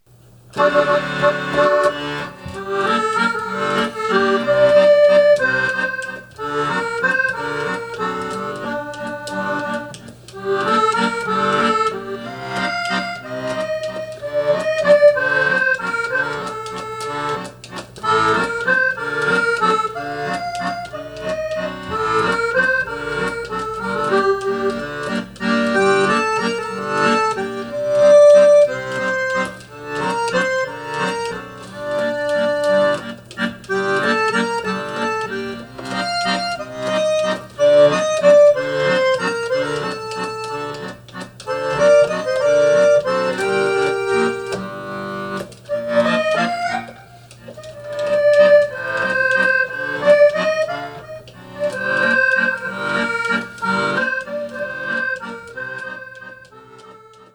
The very popular waltz for accordion solo